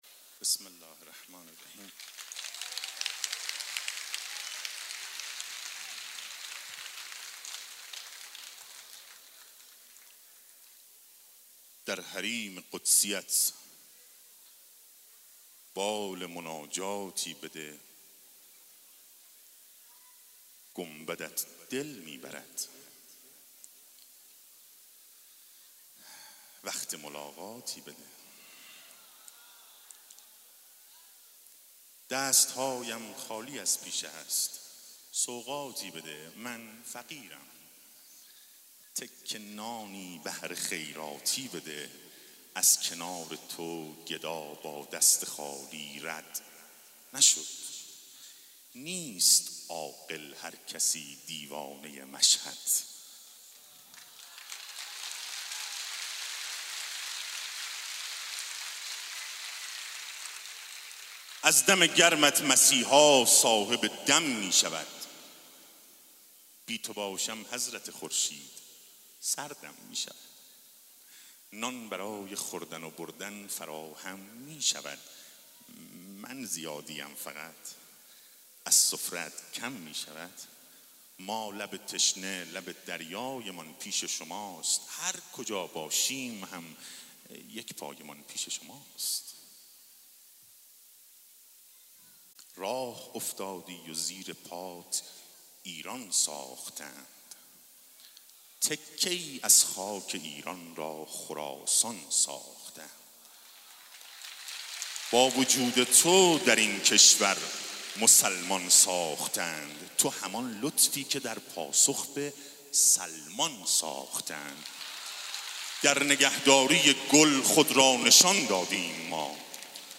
شعرخوانی
جشن ولادت حضرت علی اصغر(ع)